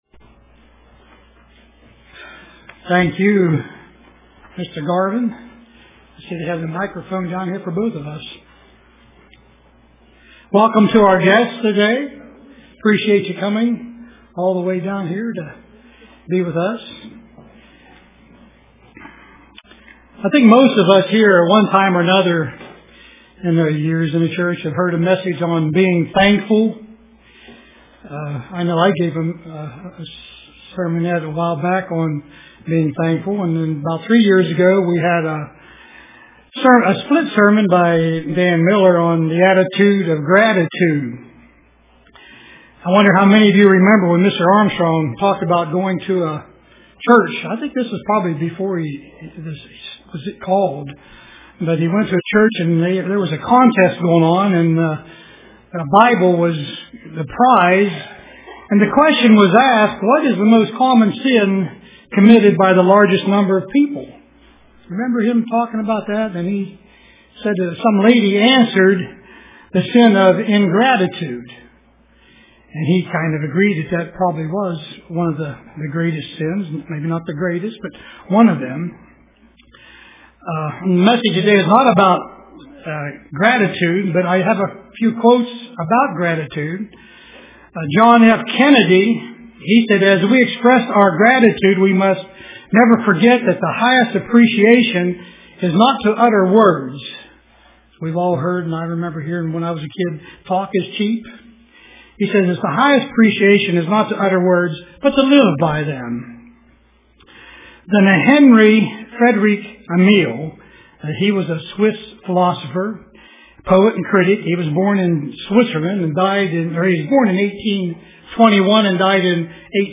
Print Epitome of Ingratitude UCG Sermon Studying the bible?